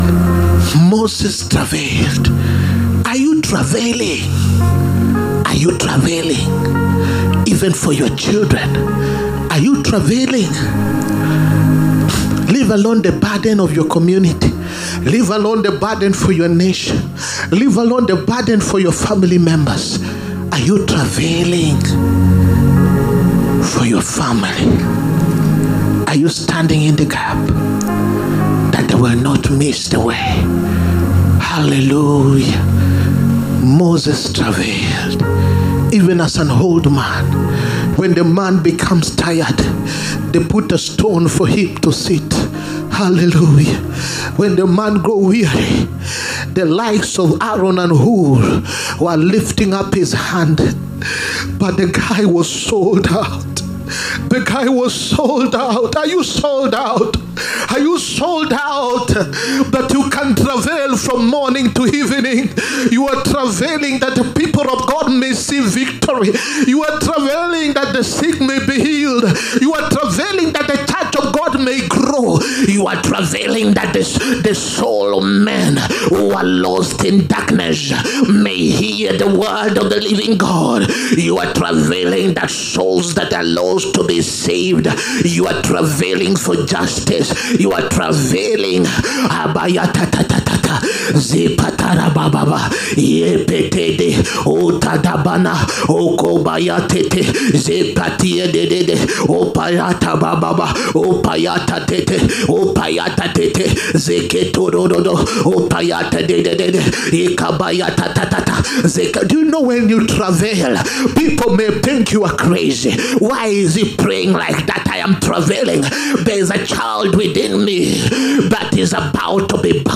SUNDAY WORSHIP SERVICE. DISCOVERING WHAT GOD HAS PLACED WITHIN YOU. 2ND MARCH 2025.